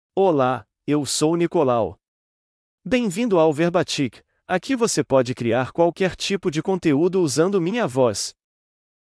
Nicolau — Male Portuguese (Brazil) AI Voice | TTS, Voice Cloning & Video | Verbatik AI
NicolauMale Portuguese AI voice
Nicolau is a male AI voice for Portuguese (Brazil).
Voice sample
Listen to Nicolau's male Portuguese voice.
Male
Nicolau delivers clear pronunciation with authentic Brazil Portuguese intonation, making your content sound professionally produced.